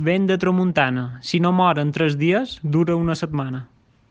Qui la pronuncia: